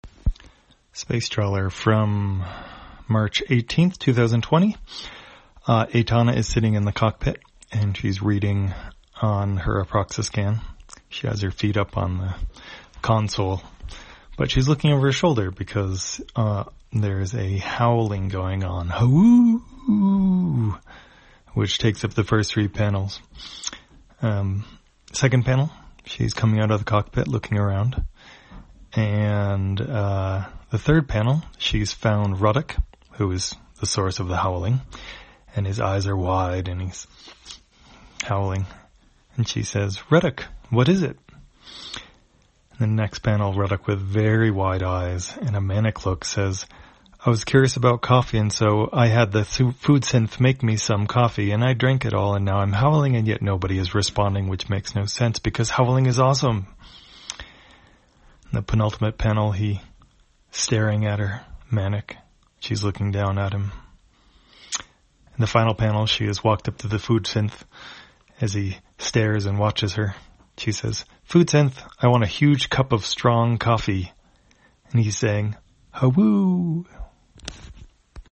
Spacetrawler, audio version For the blind or visually impaired, March 18, 2020.